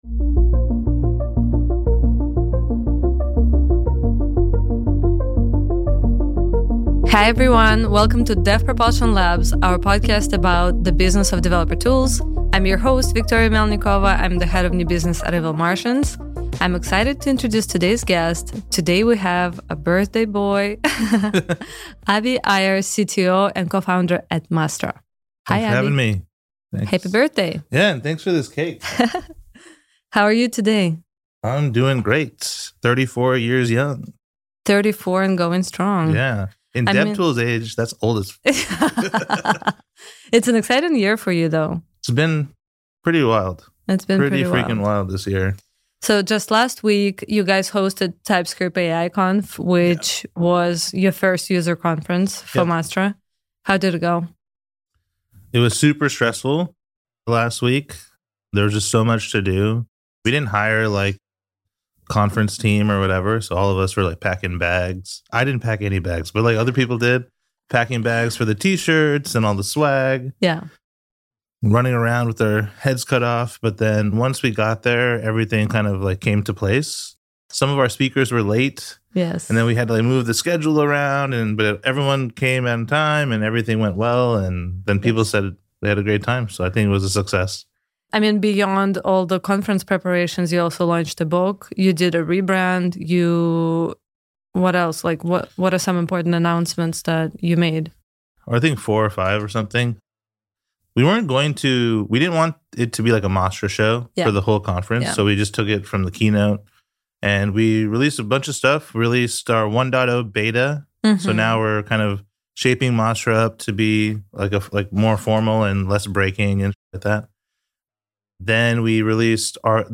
Dev Propulsion Labs is a podcast about the business of developer tools. Hosts from the Evil Martians team interview prominent dev tools founders with the goal of sharing knowledge in the maturing developer tool and commercial open source industry.